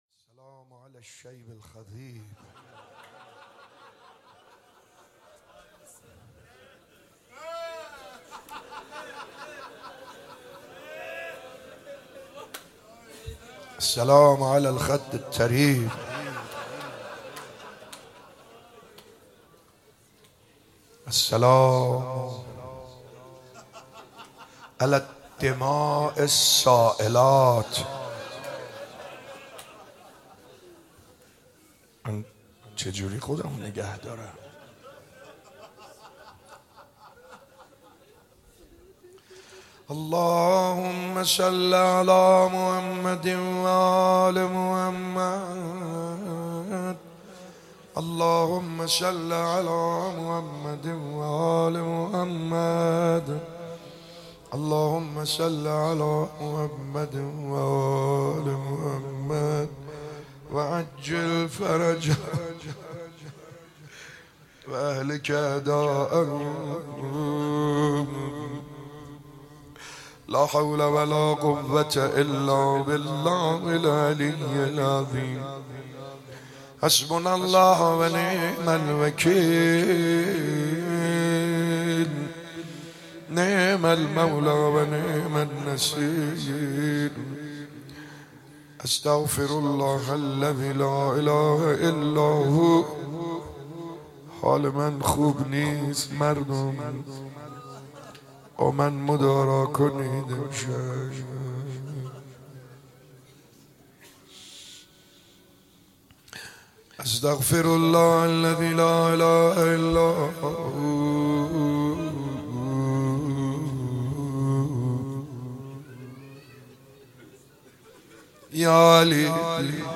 شب 29 ماه مبارک رمضان 95_روضه خوانی